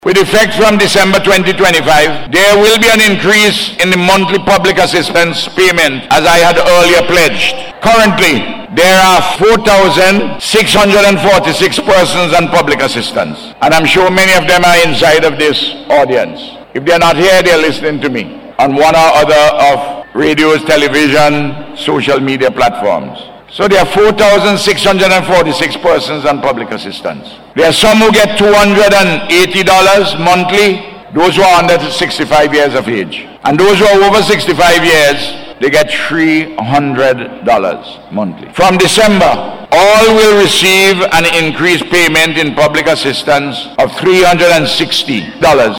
Prime Minister Dr. Ralph Gonsalves made the announcement during his Independence Day Address on Monday.